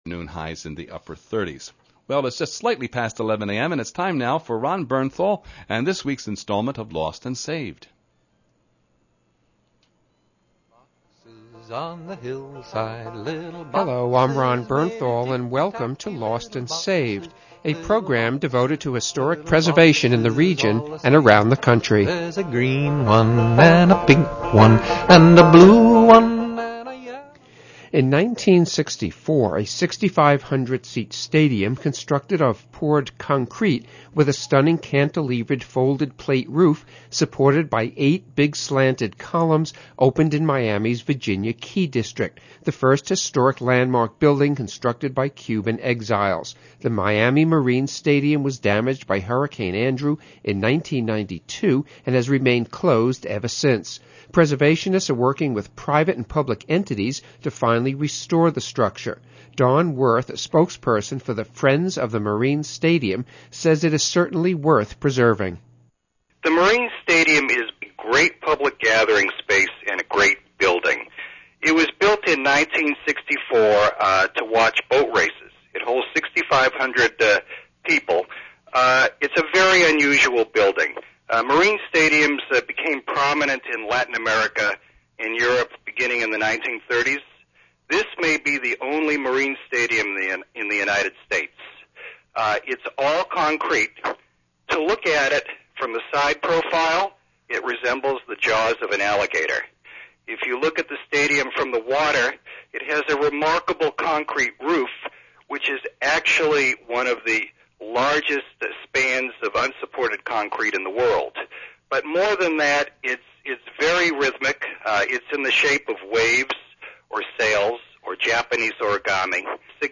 WJFF Radio Interview